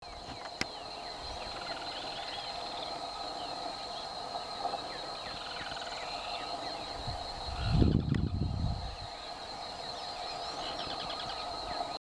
Ash-throated Crake (Mustelirallus albicollis)
Life Stage: Adult
Detailed location: Reserva Camba Trapo
Condition: Wild
Certainty: Recorded vocal